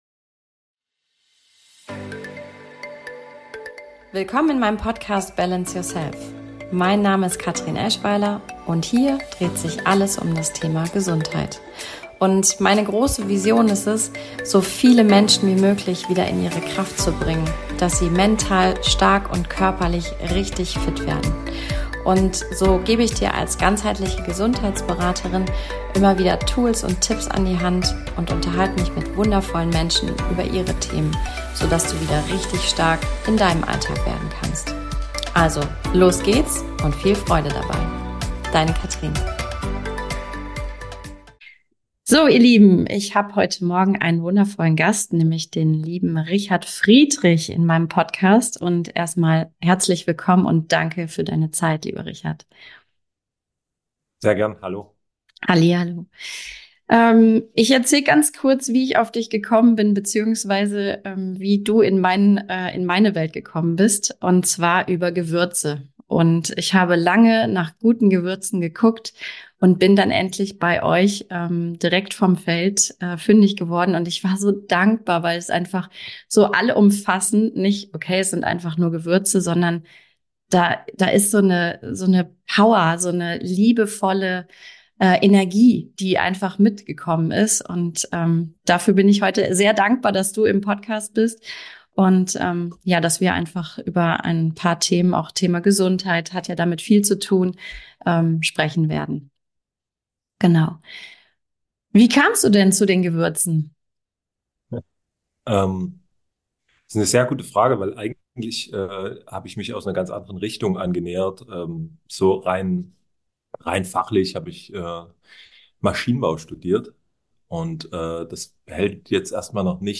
#24 - Wie ich durch die Gewürze die Welt entdecken durfte - Interview